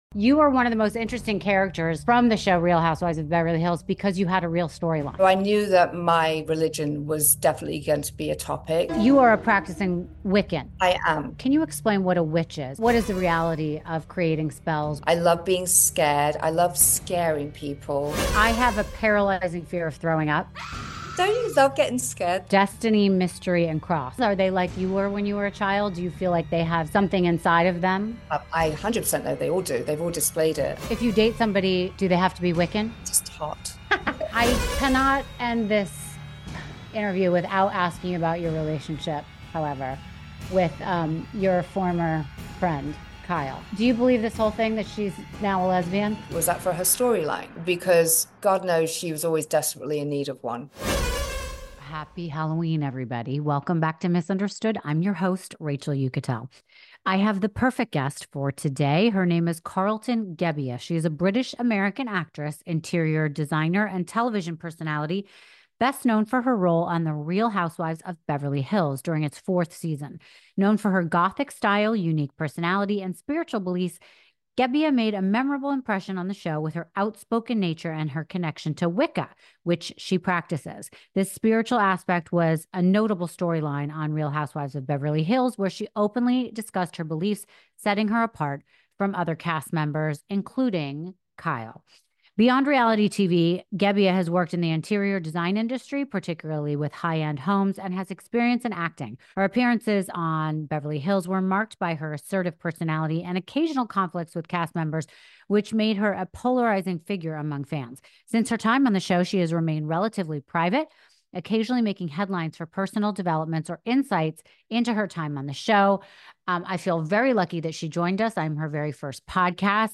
In this hauntingly beautiful conversation, Carlton opens up about: